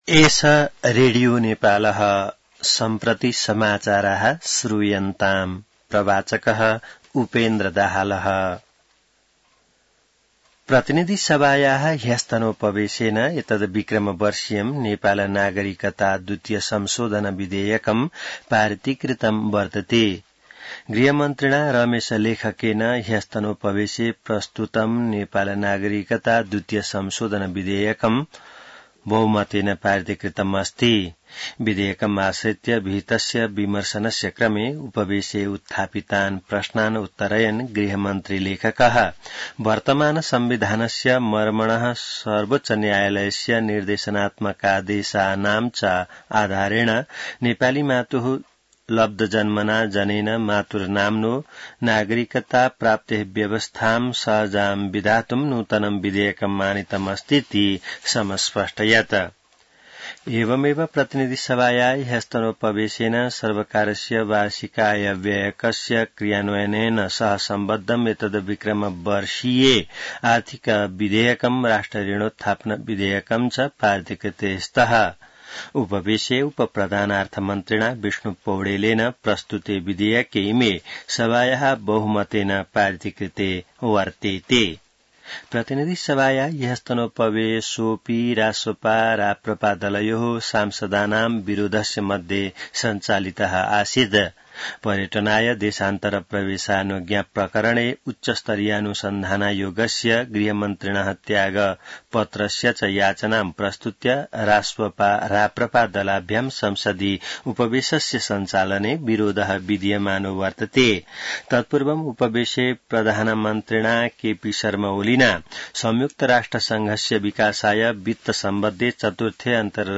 संस्कृत समाचार : १५ असार , २०८२